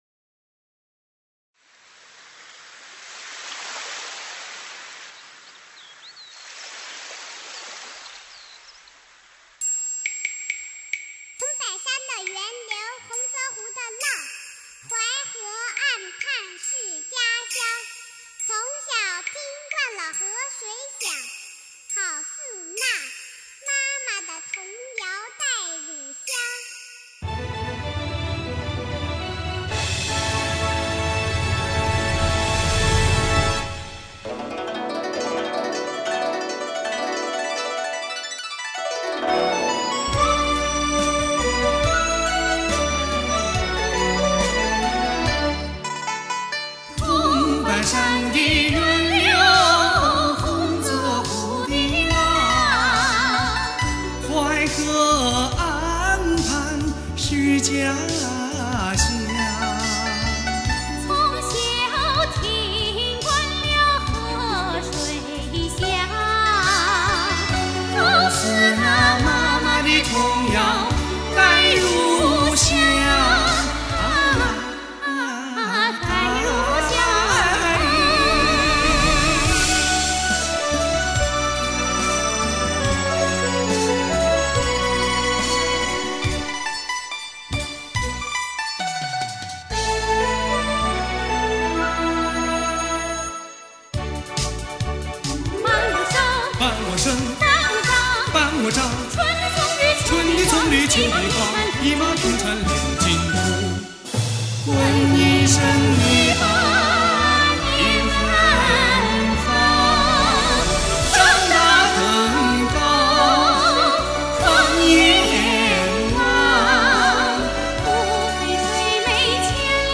[1/6/2017]泗州戏歌《淮河岸畔是家乡》 激动社区，陪你一起慢慢变老！